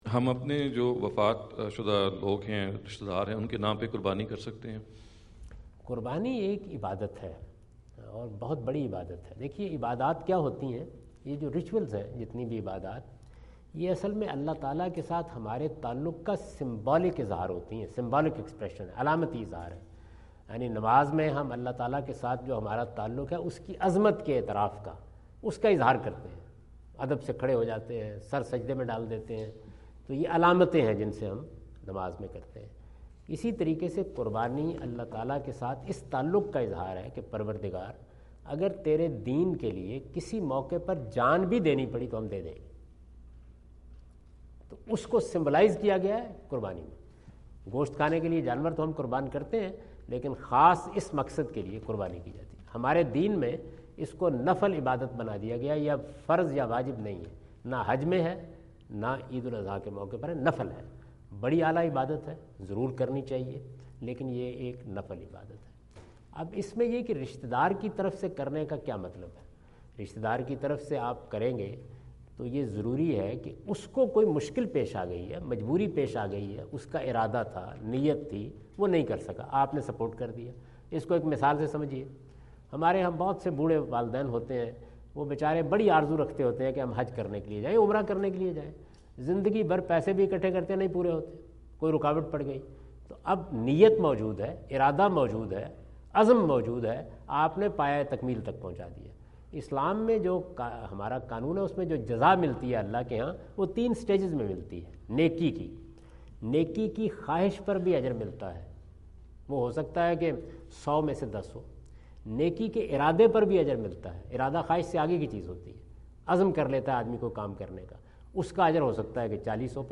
In this video Javed Ahmad Ghamidi answer the question about "animal sacrifice on behalf of others" asked at Aapna Event Hall, Orlando, Florida on October 14, 2017.